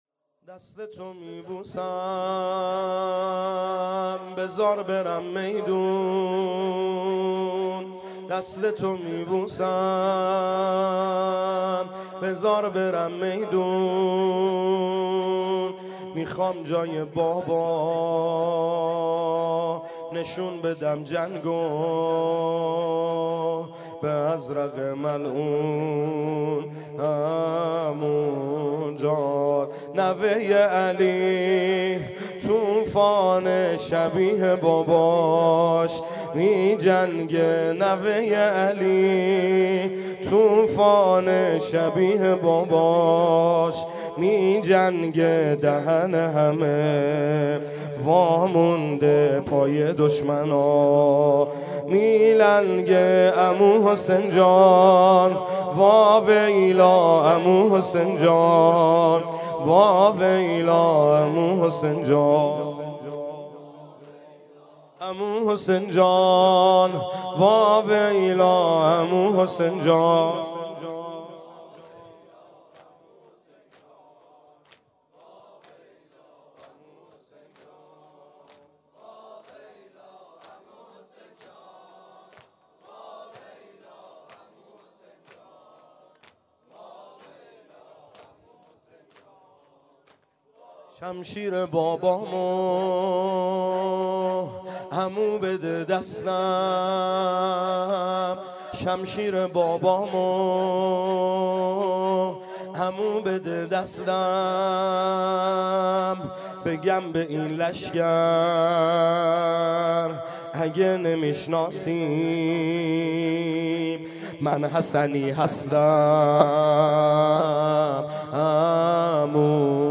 باکین - پایگاه اطلاع رسانی هیأت محبان حضرت زهرا سلام الله علیها زاهدان
زمینه | دستتو می بوسم بذار برم